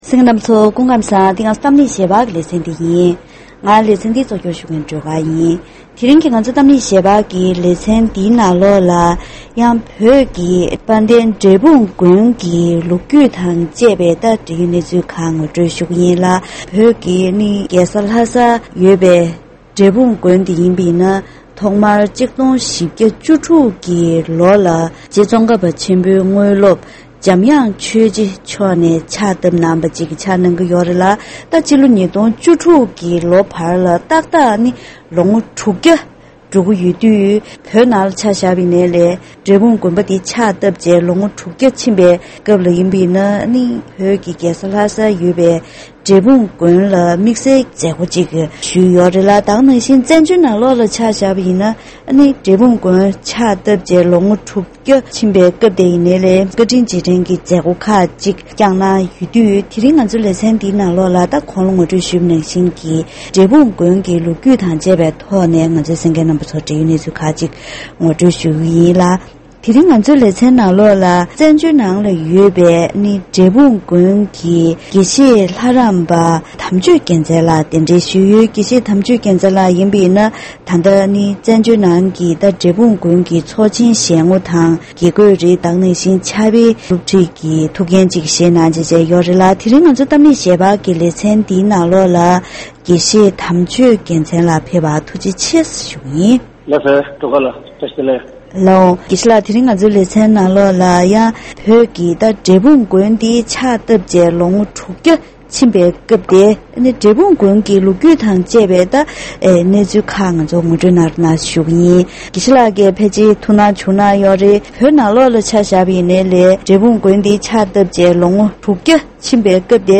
༄༅། །ཐེངས་འདིའི་གཏམ་གླེང་ཞལ་པར་ལེ་ཚན་ནང་རྗེ་ཙོང་ཁ་པ་མཆོག་གི་དངོས་སློབ་རྗེ་འཇམ་དབྱངས་ཆོས་རྗེས་ལྷ་སར་འབྲས་སྤུངས་དགོན་པ་ཕྱག་བཏབ་ནས་ལོ་ངོ་༦༠༠འགྲོ་ཡི་ཡོད་པས།